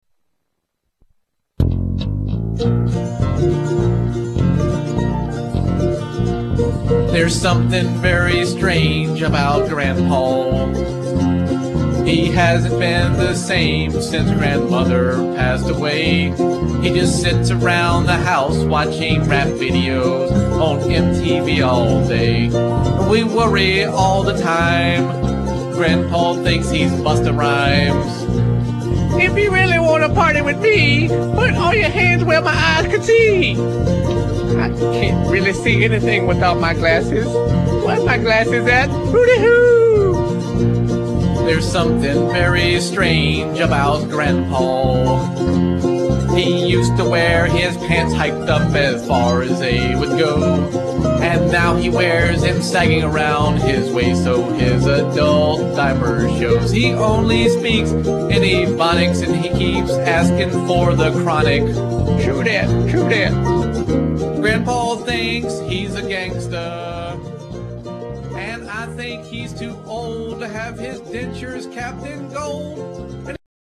--comedy skits and comedy music
Song Samples (MP3):